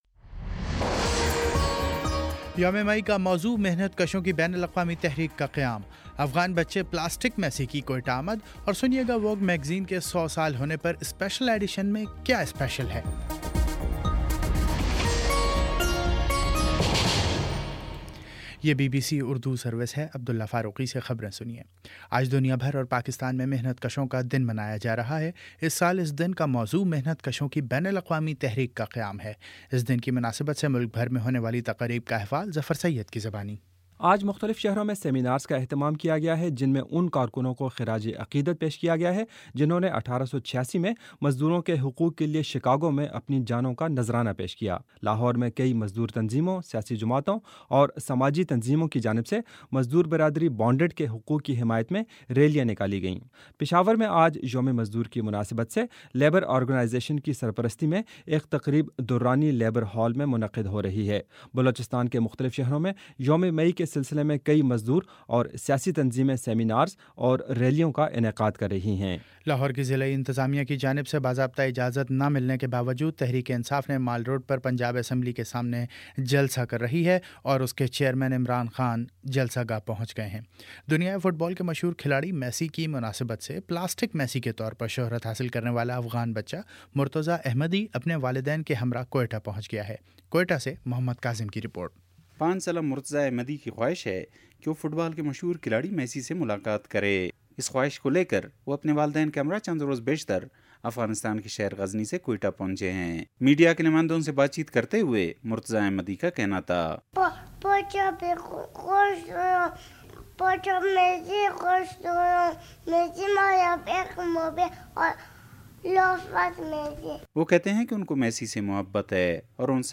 مئی 01: شام سات بجے کا نیوز بُلیٹن